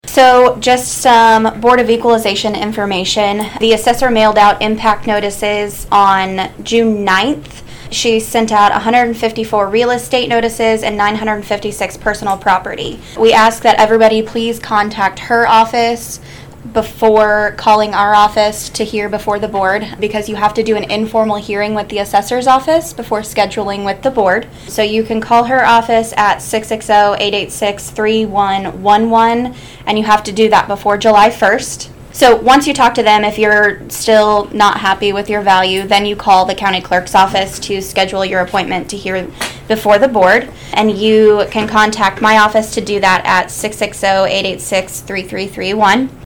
Saline County Clerk Brittni Burton gave Board of Equalization information during the Saline County Commission meeting on Wednesday, June 21.